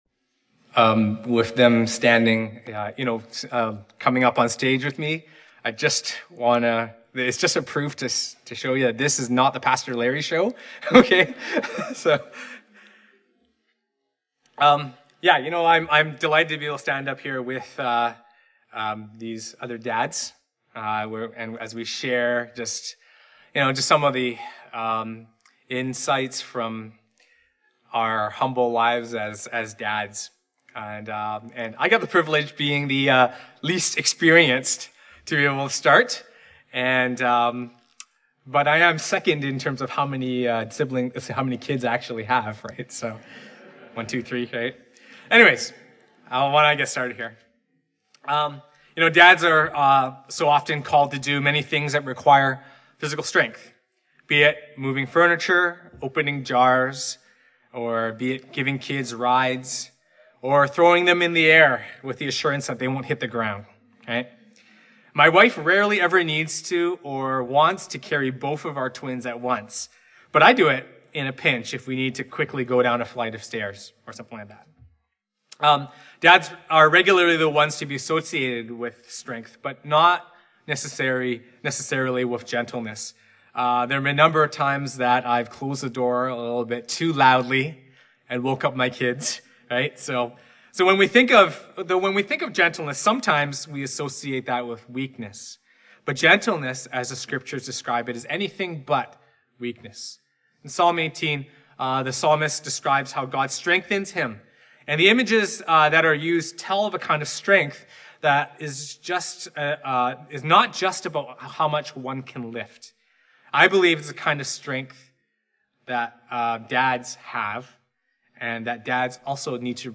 Father's Day Message.